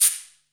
10_shaker.wav